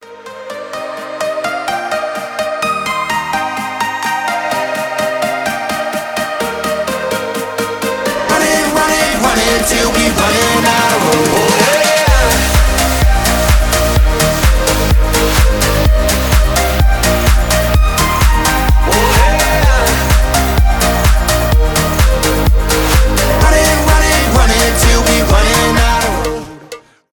танцевальные , tropical house
progressive house